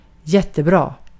Ääntäminen
Vaihtoehtoiset kirjoitusmuodot (vanhahtava) greate (rikkinäinen englanti) gurt Synonyymit large thick beautiful noble deep splendid wonderful massive all that and a bag of chips Ääntäminen : IPA : /ˈɡɹeɪt/ US : IPA : [ɡɹeɪt]